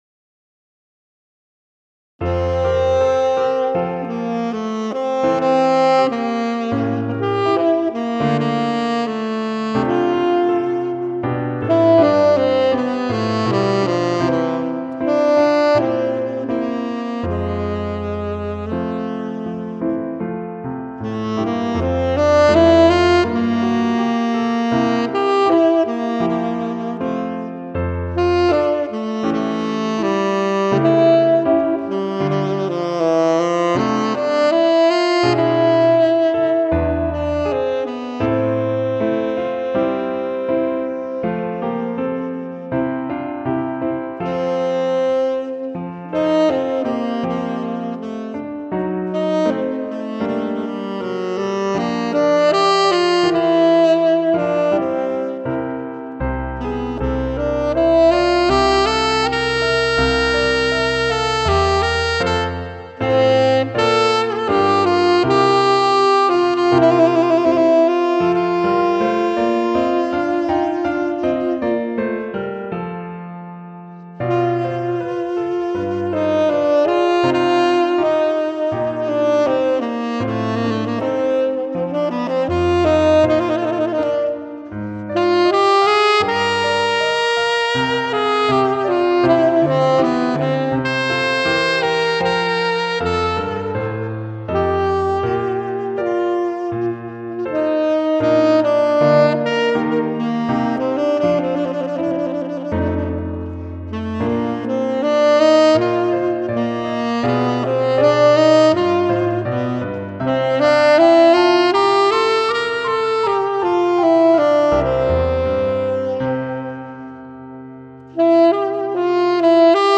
Sop/Tenor Sax & Piano sample: